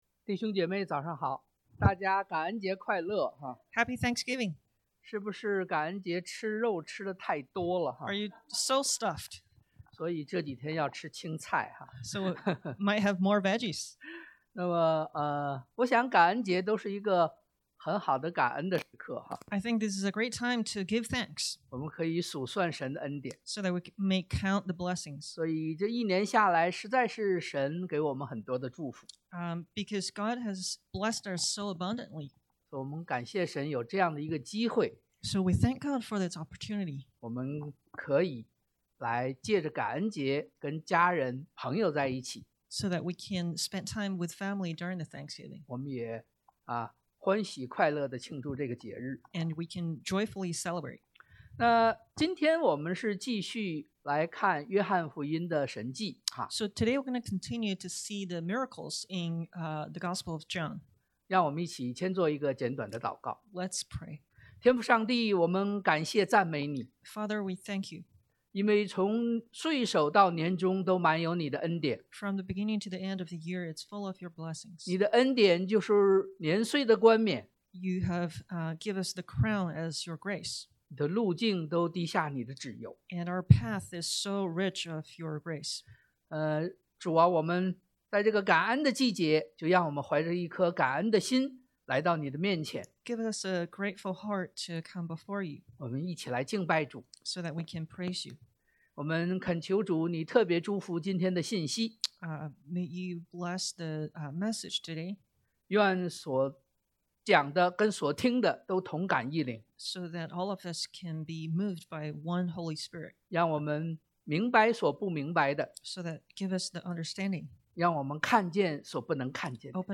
約John 9:1-41 Service Type: Sunday AM 1.